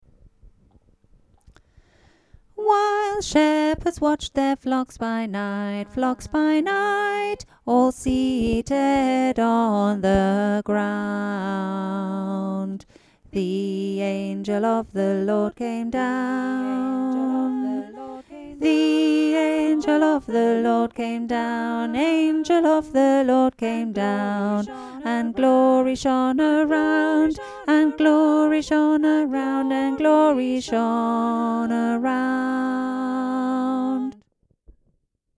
Traditional 'village' carols in pubs
While_Shepherds_Watched_Cranbrook_tenor.mp3